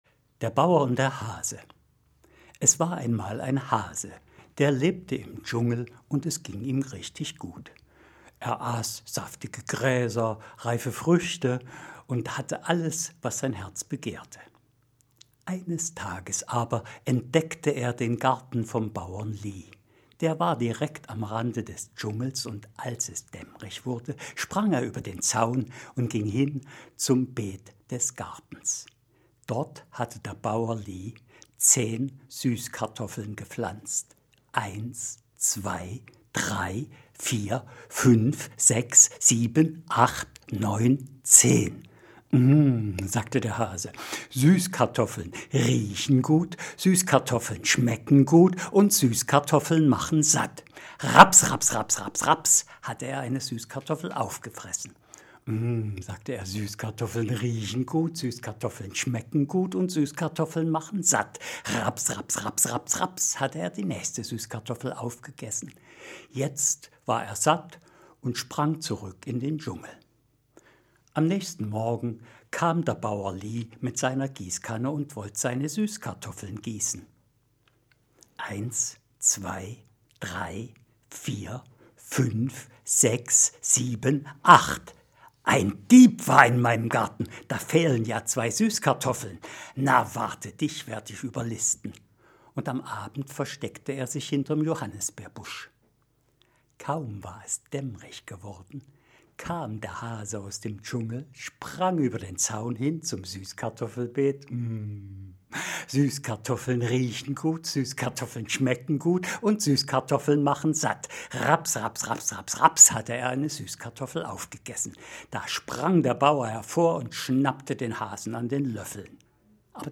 Lesung: